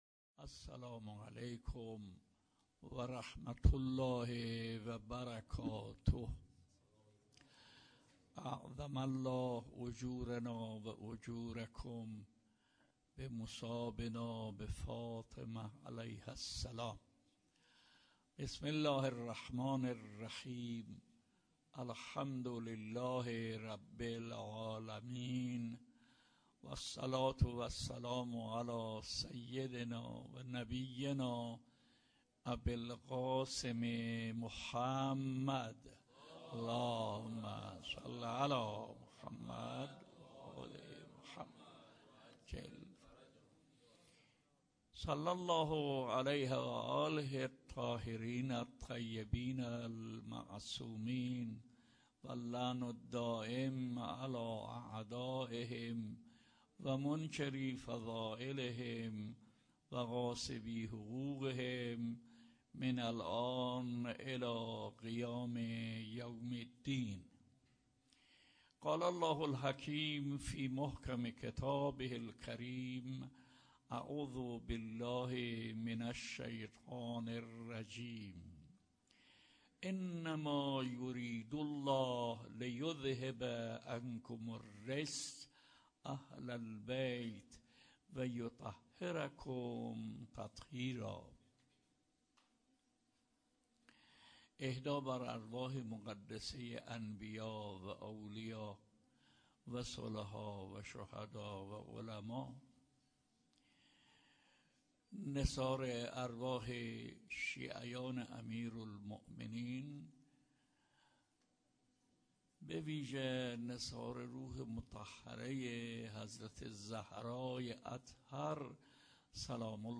شب اول ویژه برنامه فاطمیه دوم ۱۴۳۹